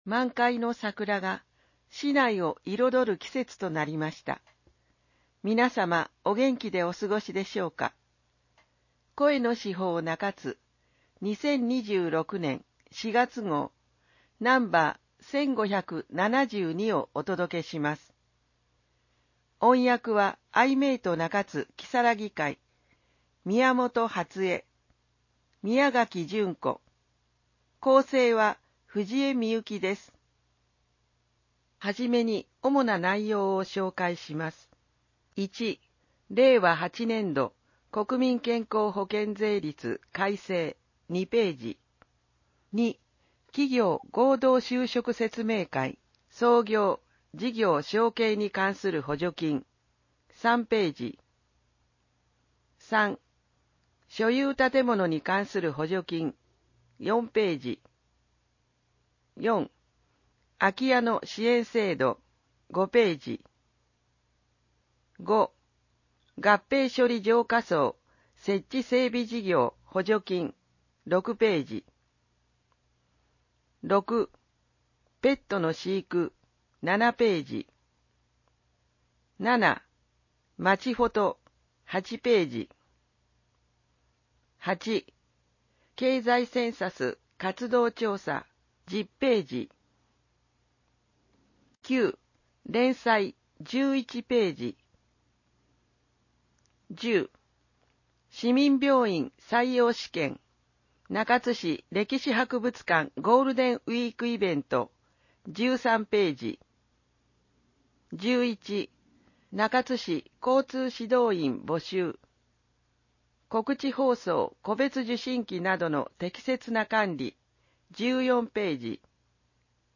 市報の内容を音声で聞くことができます。 アイメイト中津きさらぎ会がボランティアで製作しています。